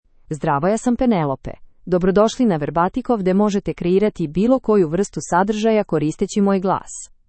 FemaleSerbian (Serbia)
Penelope — Female Serbian AI voice
Penelope is a female AI voice for Serbian (Serbia).
Voice sample
Penelope delivers clear pronunciation with authentic Serbia Serbian intonation, making your content sound professionally produced.